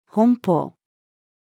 奔放-female.mp3